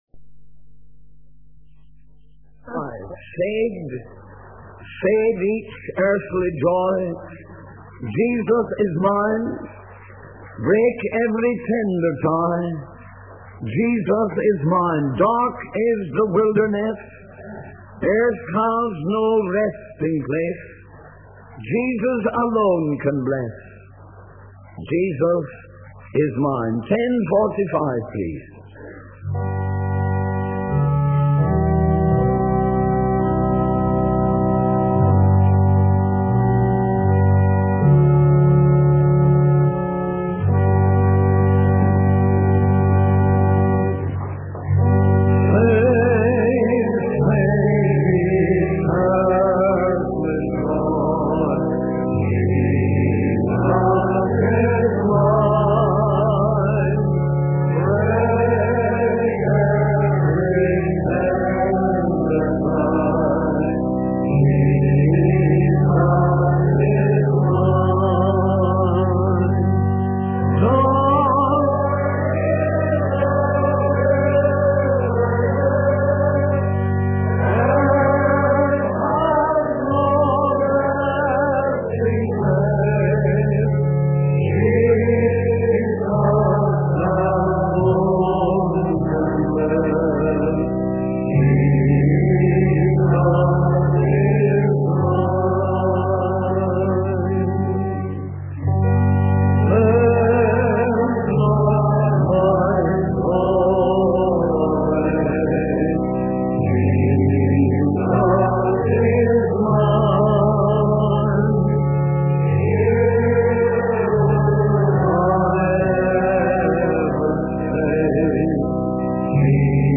In this sermon, the preacher discusses the teachings of Peter in just four verses. He emphasizes that Peter knew about various important doctrines such as connection, sanctification, regeneration, appropriation, examination, adoration, inspiration, and redemption.